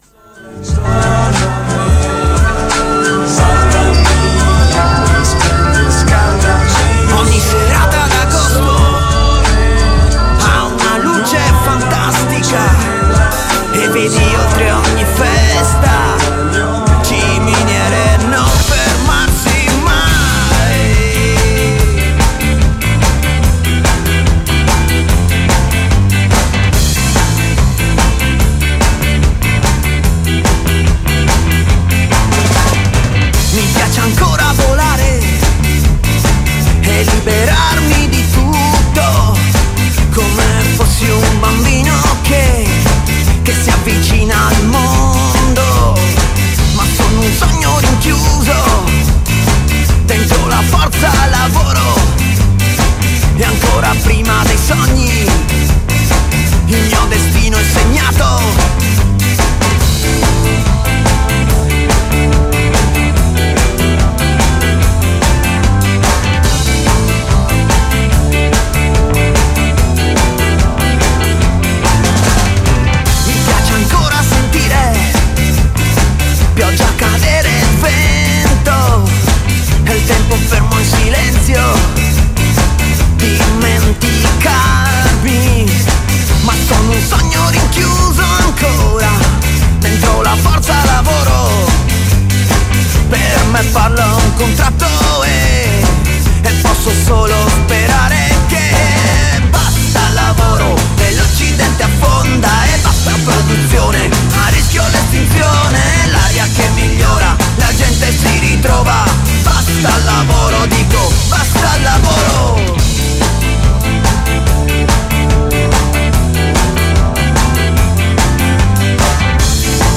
Collegamento con un avvocato del lavoro con cui discutiamo degli ultimi decreti attuativi del Jobs Act (in materia di controllo a distanza dei lavoratori e legalizzazione del demansionamento).